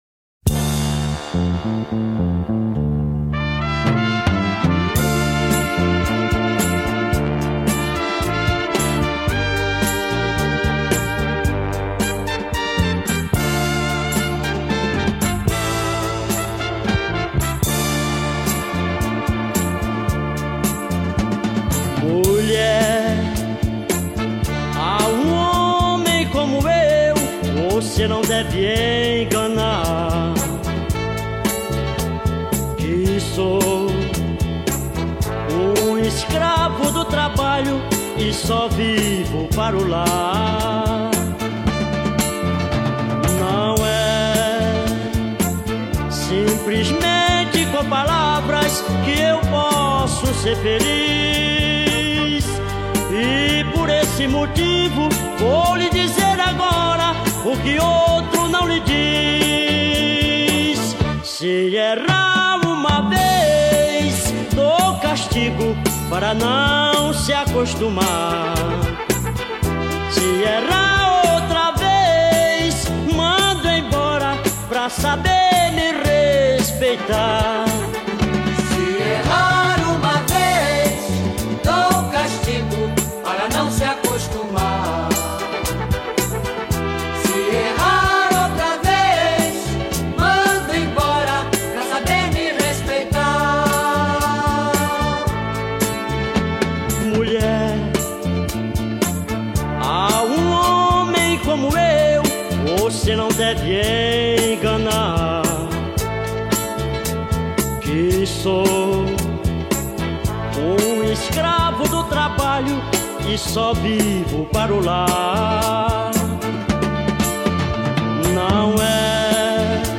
A PAUTA DA BOA MUSICA OFERECE MAIS UMA BELESURA BREGA